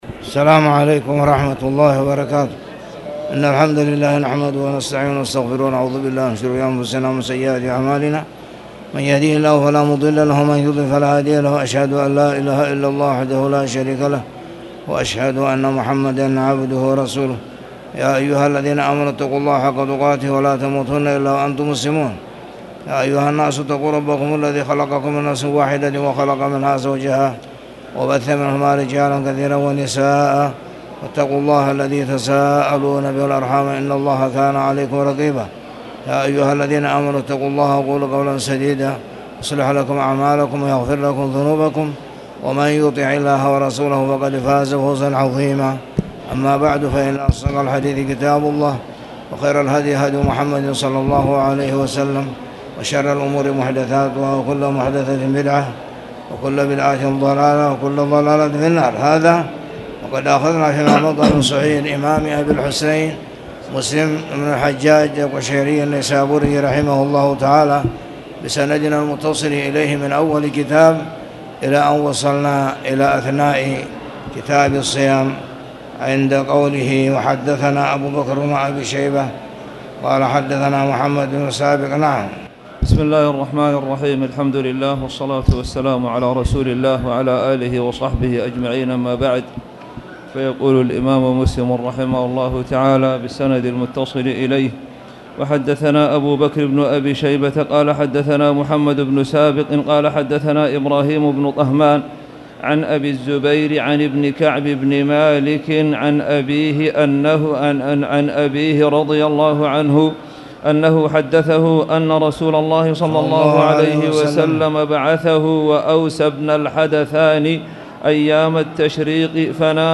تاريخ النشر ٢٩ محرم ١٤٣٨ هـ المكان: المسجد الحرام الشيخ